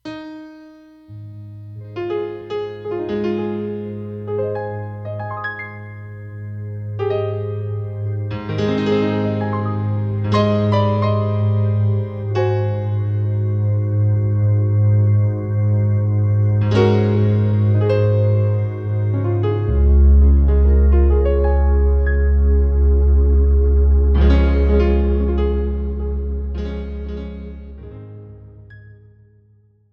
This is an instrumental backing track cover.
• Key – A♭
• Without Backing Vocals
• No Fade
Backing Track without Backing Vocals.